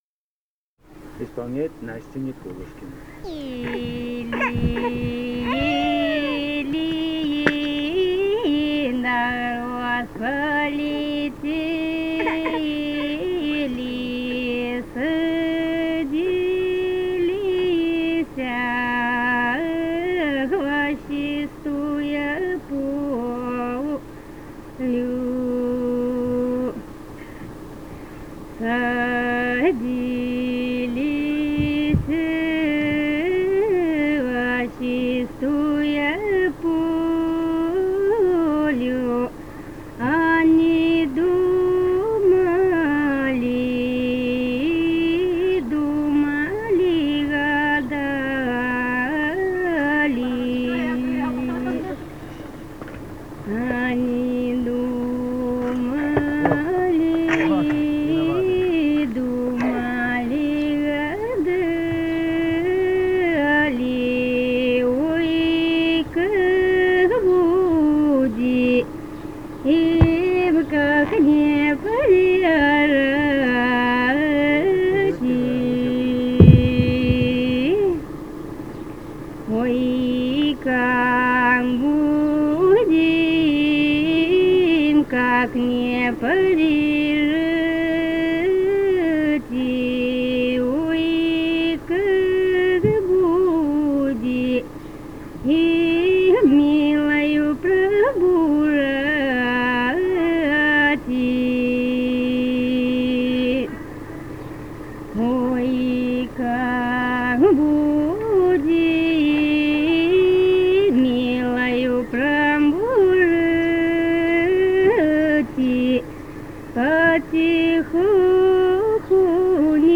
Ставропольский край, пос. Новокумский Левокумского района, 1963 г. И0719-15